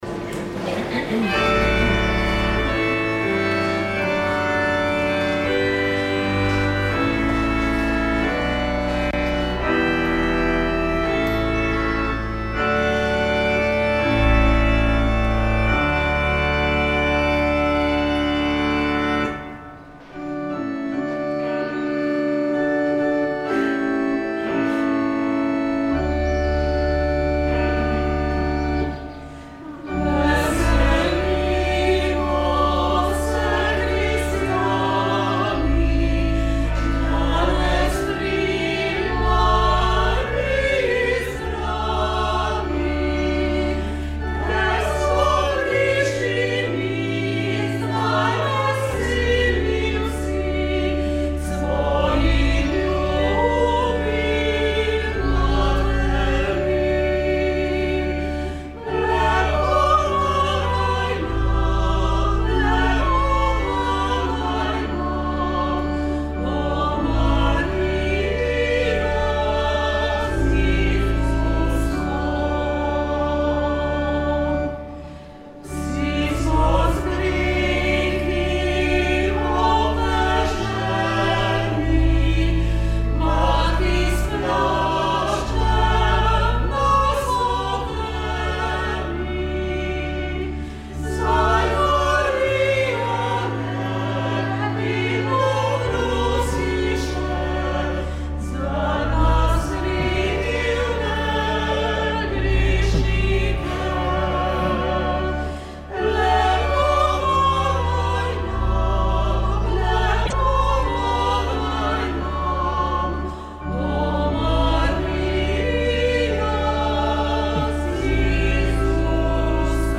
Sveta maša
Prenos pogrebne slovesnosti zaslužnega papeža Benedikta XVI.
Pogrebno sveto mašo je daroval papež Frančišek. Z njim je somaševalo 3700 duhovnikov, ter mnogi kardinali in škofje, med njimi tudi nadškof Stanislav Zore.